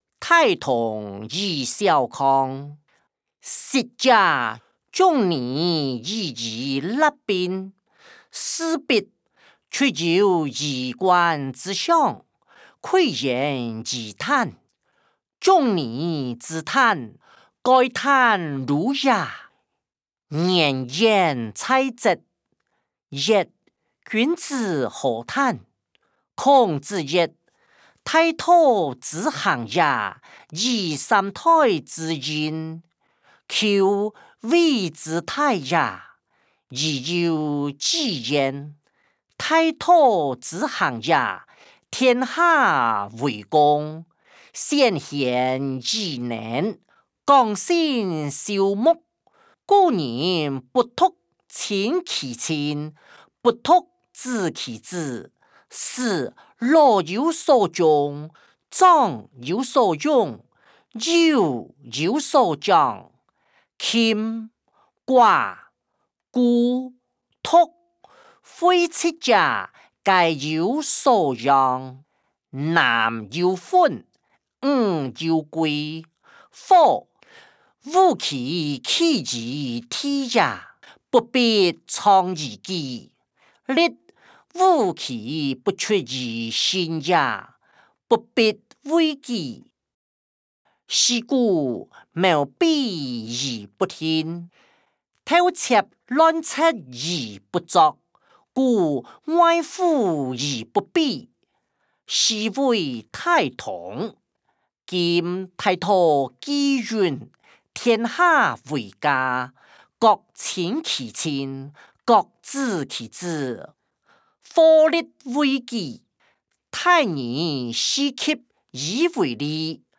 歷代散文-張釋之執法音檔(海陸腔)